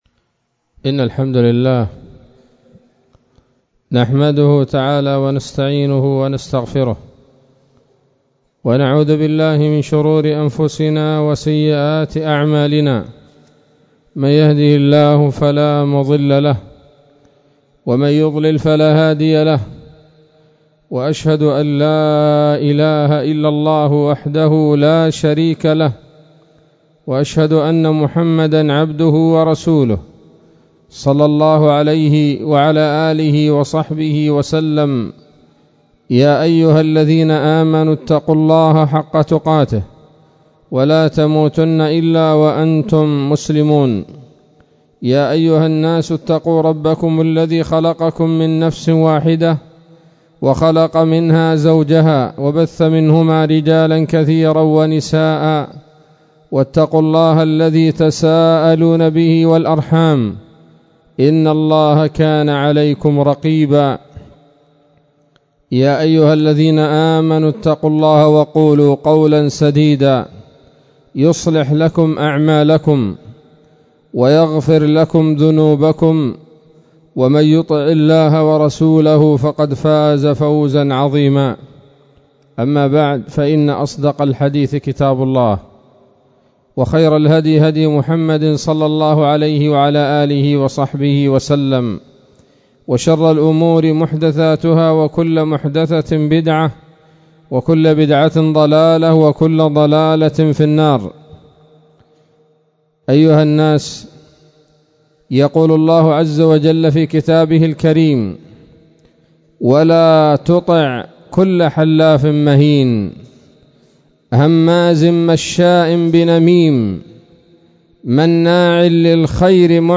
خطبة جمعة بعنوان: (( النمامون )) 9 من شهر جمادى الآخرة 1442 هـ